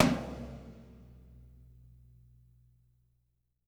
-TOM 2P   -R.wav